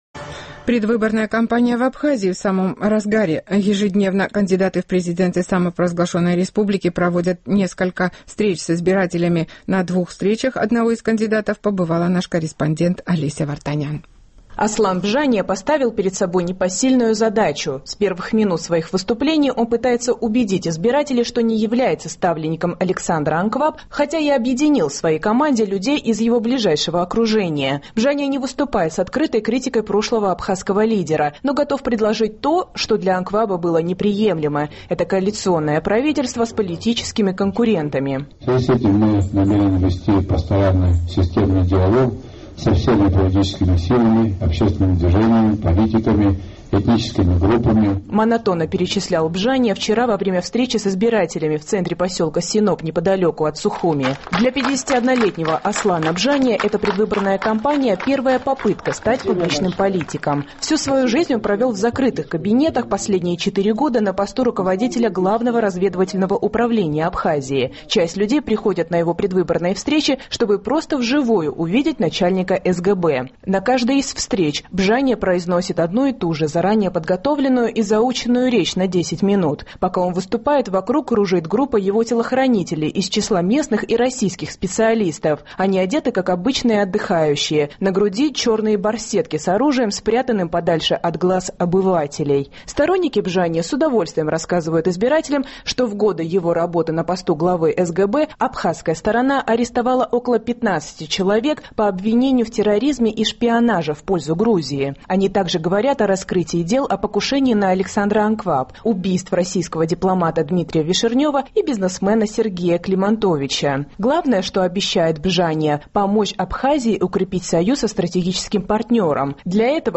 «Мы должны вести системный диалог со всеми политическими силами, общественными движениями, политиками, этническими группами», – монотонно перечислял Бжания вчера во время встречи с избирателями в центре поселка Синоп неподалеку от Сухуми.
На каждой из встреч Бжания произносит одну и ту же заранее подготовленную и заученную речь на 10 минут.